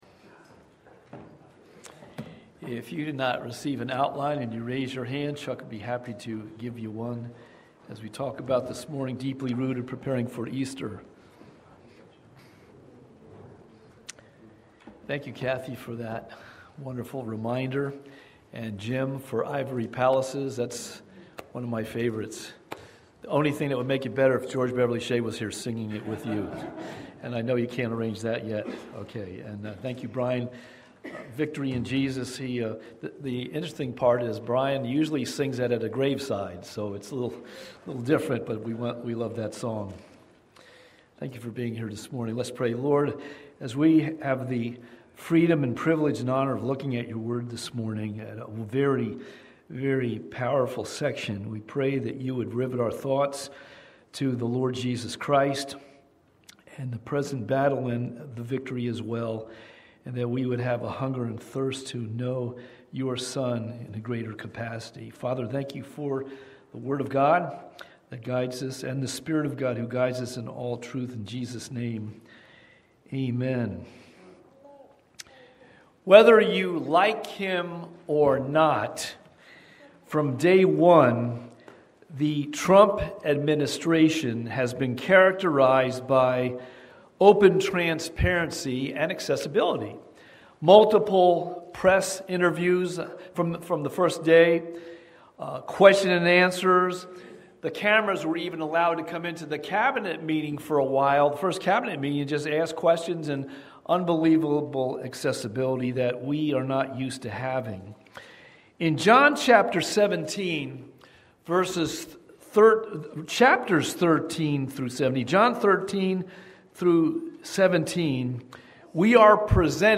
Watch Online Service recorded at 9:45 Sunday morning.
Sermon Audio